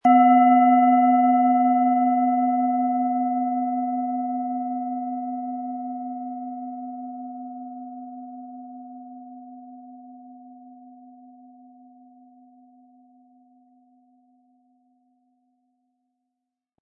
Planetenton 1
Nach uralter Tradition von Hand getriebene Klangschale.
Spielen Sie die Sonne mit dem beigelegten Klöppel sanft an, sie wird es Ihnen mit wohltuenden Klängen danken.